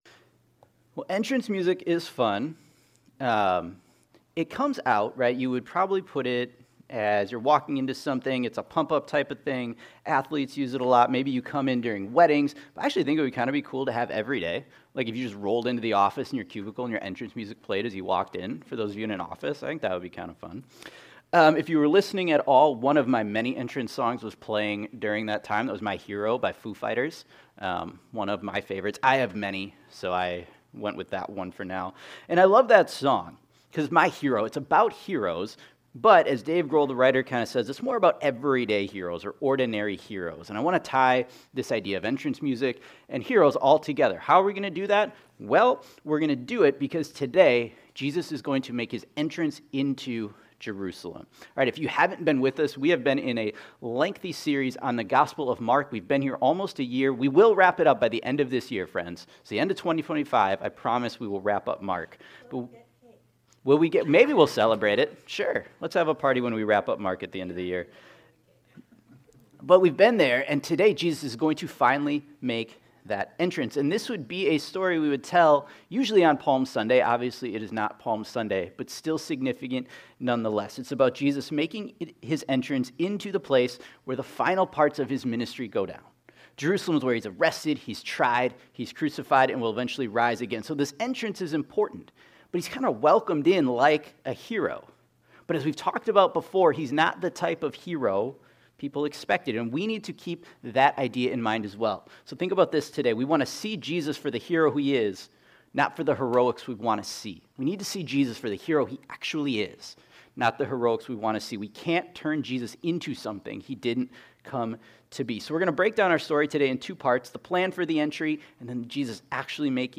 Mark Entrance Faith Hero Obedience Plans Sacrifice Suffering Sunday Morning In the opening of Mark 11, Jesus makes His triumphal entry into Jerusalem.